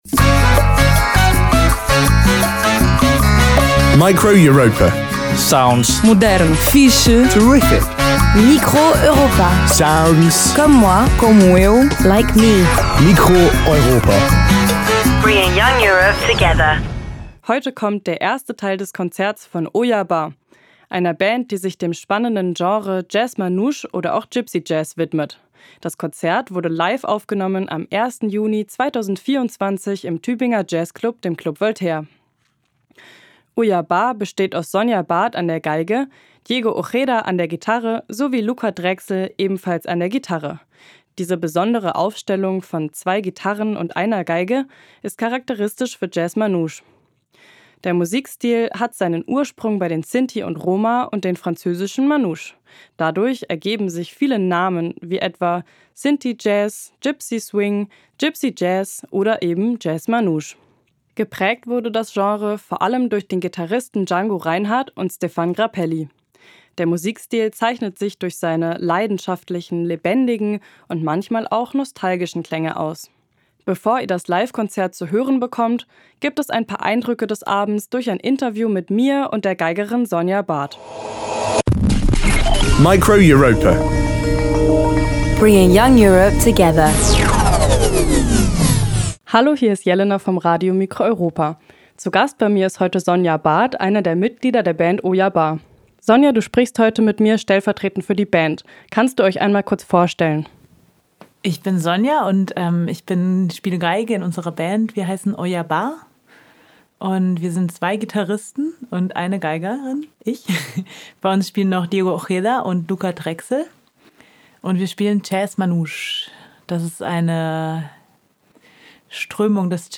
Gipsy Jazz
Geige
Gitarre
Form: Live-Aufzeichnung, geschnitten